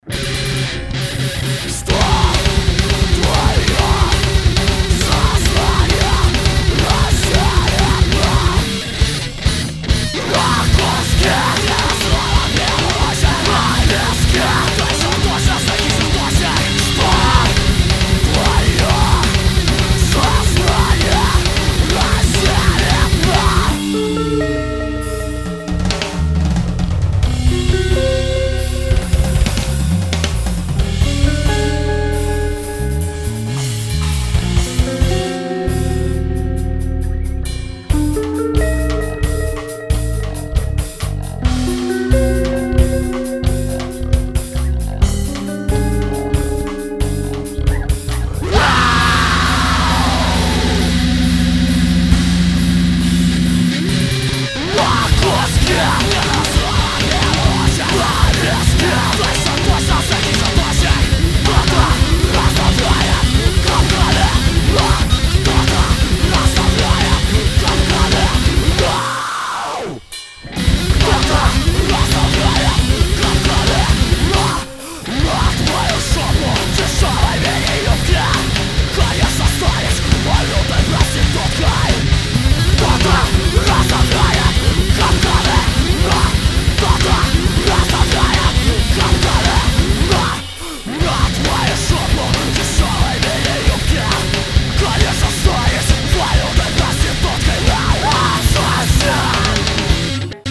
вот 1 песня со 2 проэкта) больше для любителей тяжелой музыки)
Припев норм.)